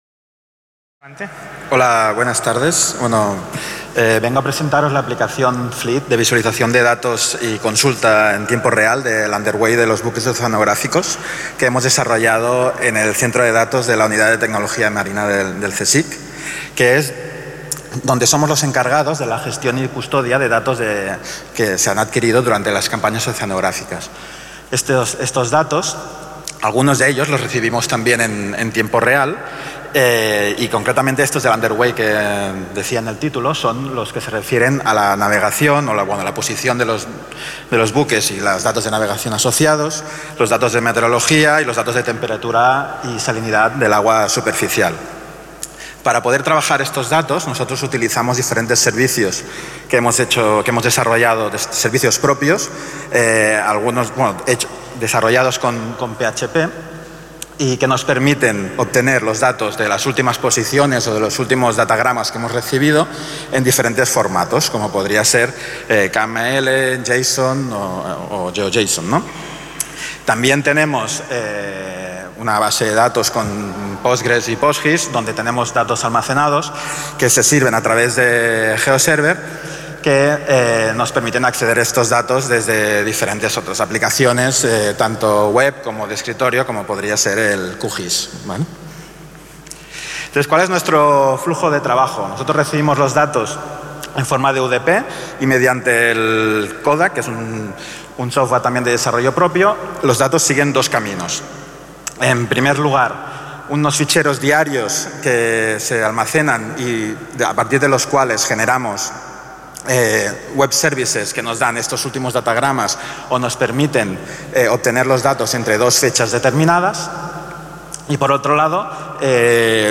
Xerrada en el marc de les 17enes Jornades de SIG Lliure 2024 organitzades pel SIGTE de la Universitat de Girona de dos membres de La Unitat de Tecnologia Marina del CSIC sobre l'aplicació Fleet que gestiona dades de vaixells oceanogràfics en temps real, disponibles mitjançant serveis web per a usuaris externs i interns.